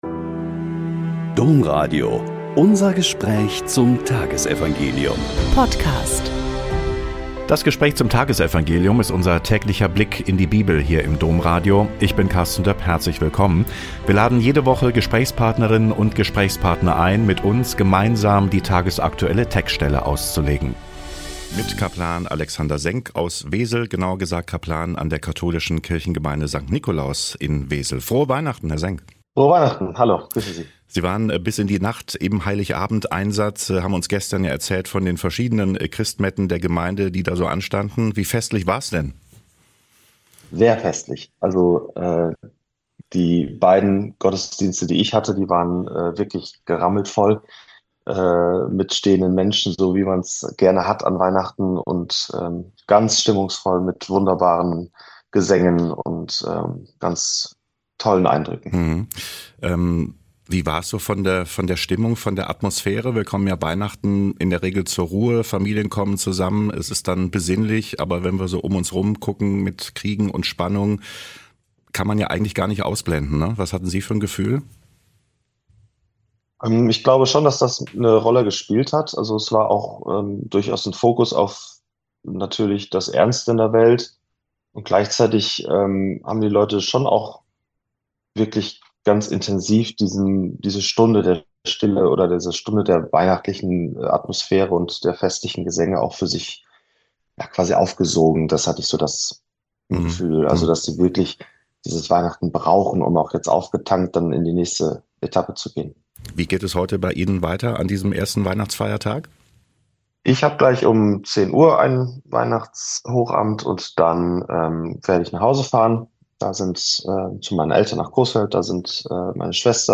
Lk 2,15-20 - Gespräch